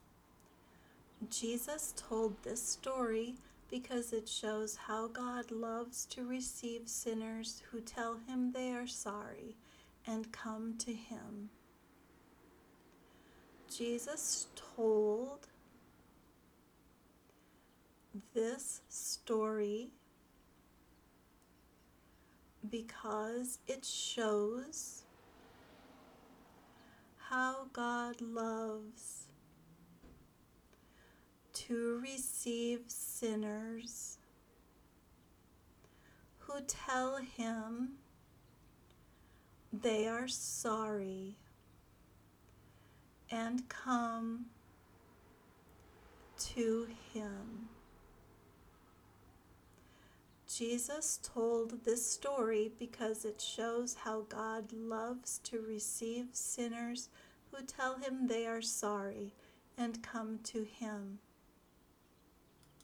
The Prodigal Son Speaking Exercise